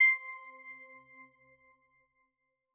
Button_1_Pack2.wav